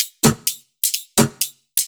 Index of /VEE/VEE2 Loops 128BPM
VEE2 Electro Loop 117.wav